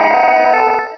Cri de Deoxys dans Pokémon Rubis et Saphir.